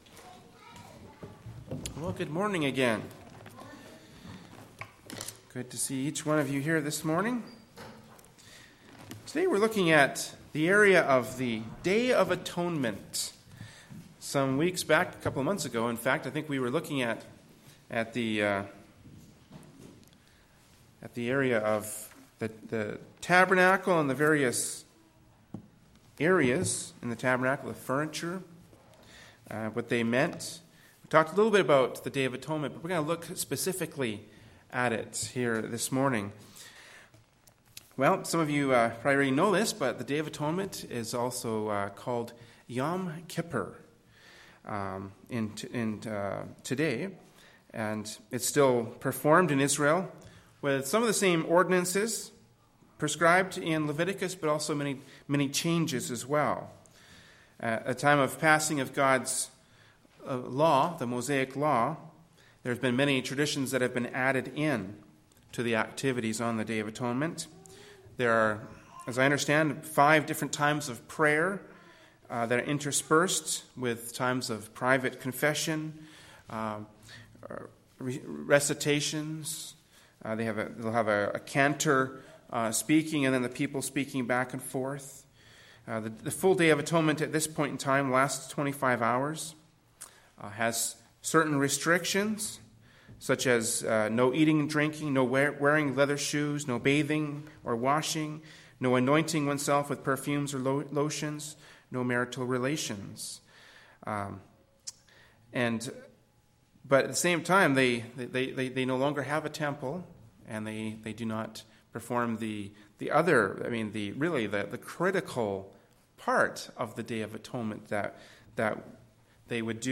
Kamloops, B.C. Canada
Morning Worship Service